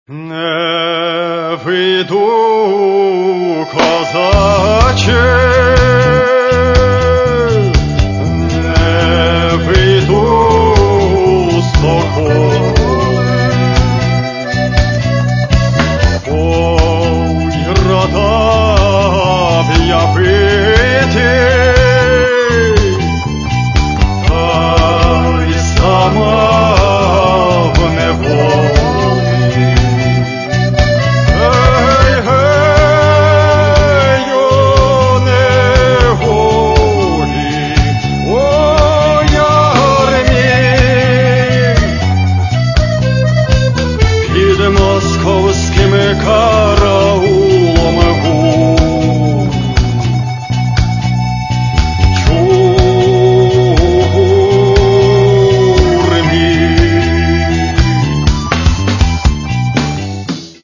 Каталог -> Рок и альтернатива -> Фольк рок
соединяя энергию украинских, цыганских, ямайских мелодий.